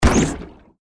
diving_game_hit.ogg